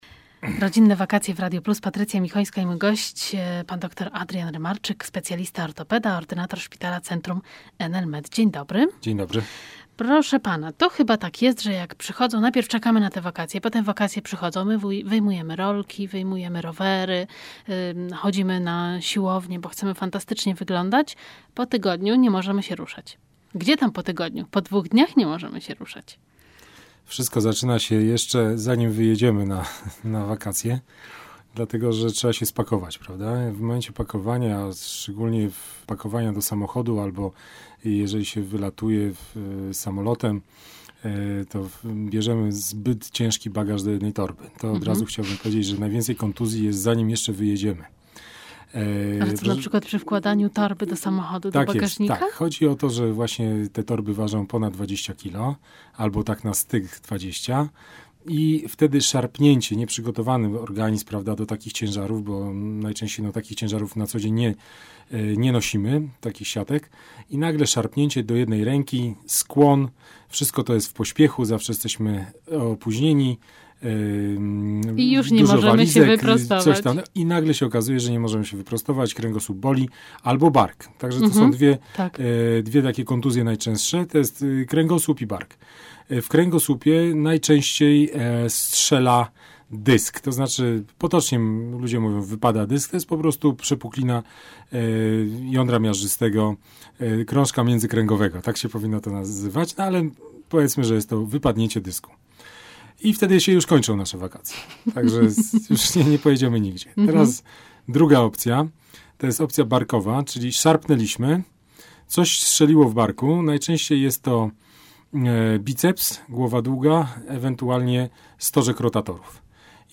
Wywiad z ortopedą w Radio Plus - Jedziemy na wakacje